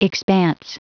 Prononciation du mot expanse en anglais (fichier audio)
Prononciation du mot : expanse